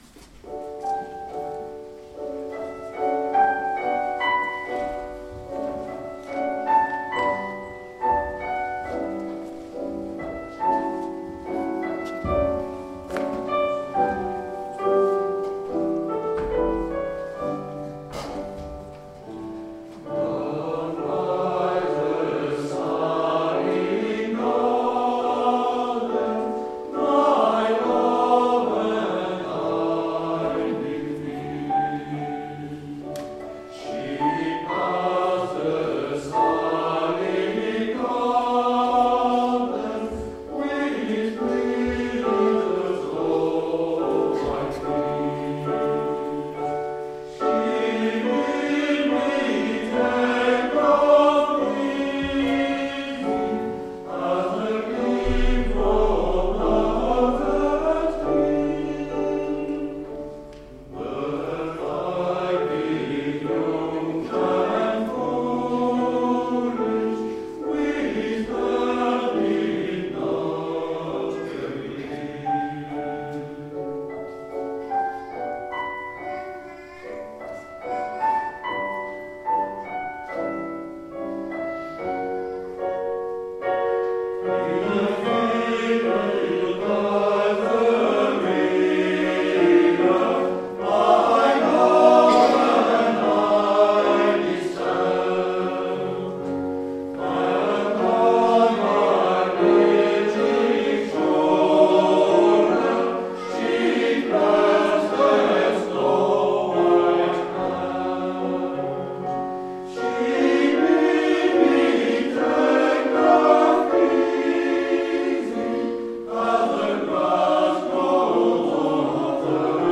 Extraits audios du concert du dimanche 12 mai 2013
Chorale HARMONI'HOM de Saint Pavace  Down by the sally gardens  J. Rutter Harmoni'Hom down by the sally gardens
17h00 : concert des trois chorales à l'Eglise Saint Bertrand du Mans :